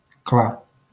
wymowa:
IPA[ˈkla]